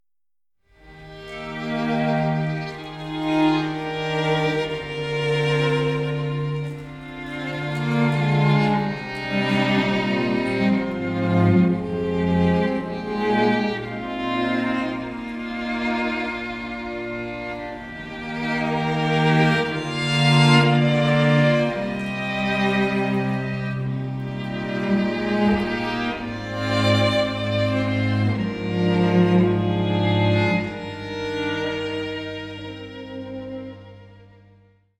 Streichensemble
• kurzweilige Zusammenstellung verschiedener Live-Aufnahmen